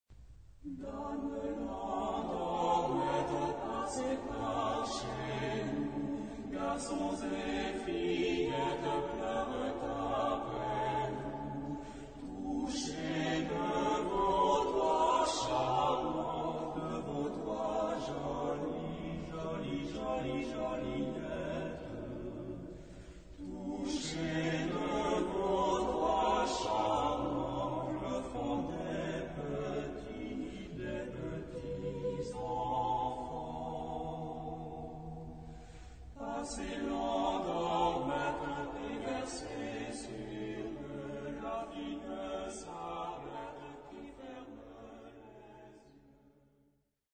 Genre-Style-Form: Popular ; Secular
Mood of the piece: moderate
Type of Choir: SATB  (4 mixed voices )
Tonality: E minor ; G minor
Origin: Vendée (F)